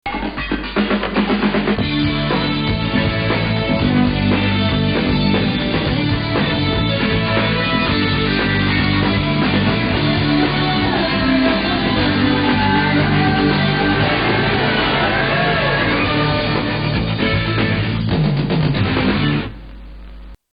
The theme is instrumental and fairly unremarkable